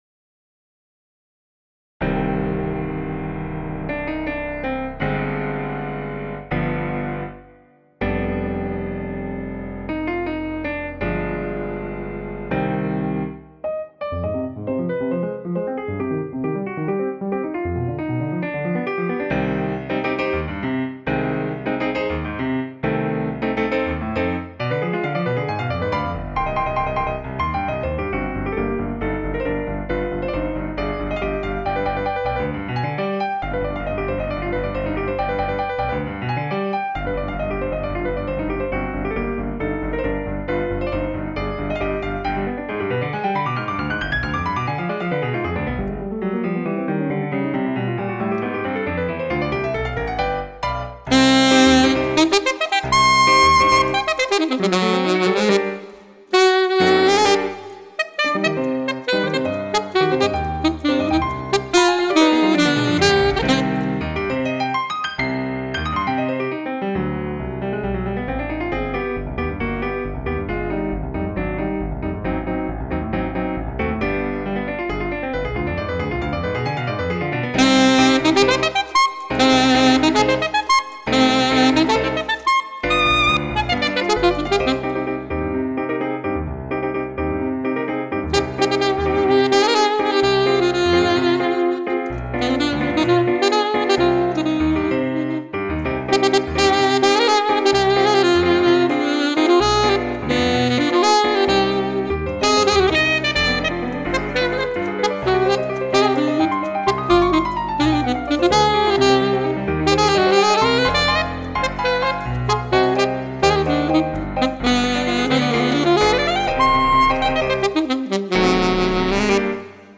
alto saxophone and piano